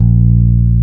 -MM JAZZ A#2.wav